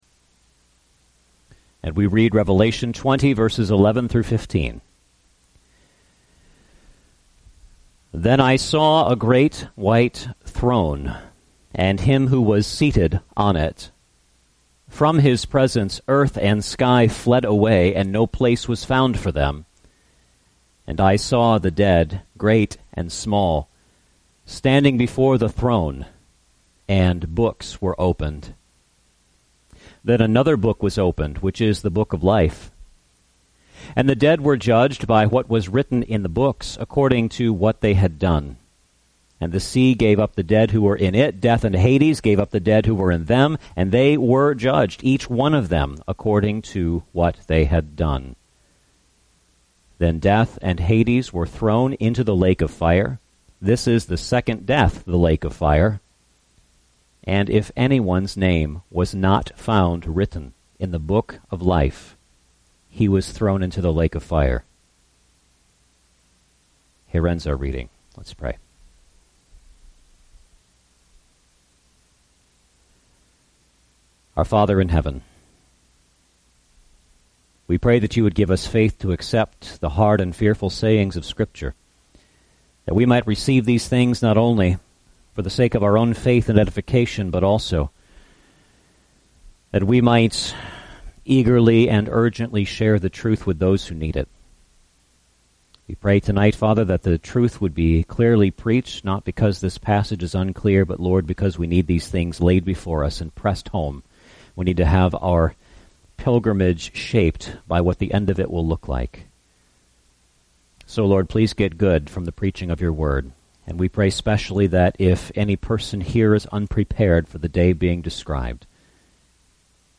Revelation 20:11-15 Service Type: Sunday Evening Service Handout